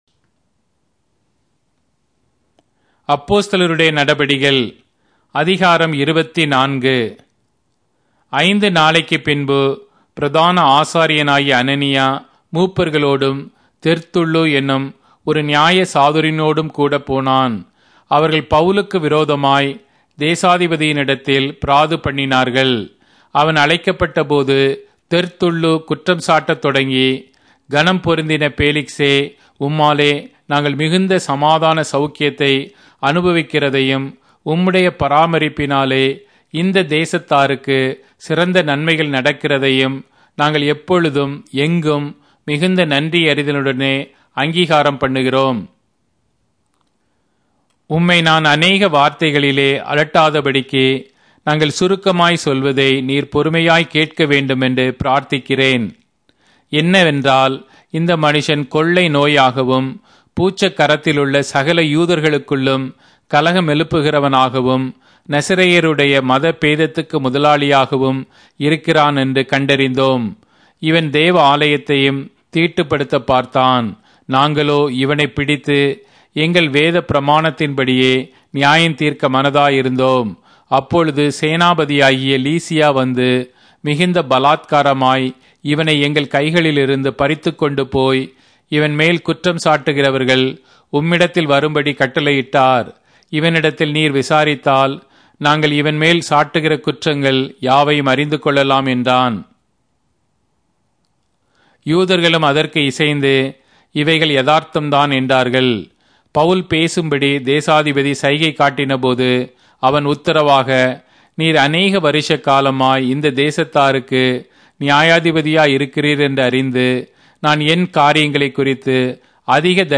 Tamil Audio Bible - Acts 22 in Mhb bible version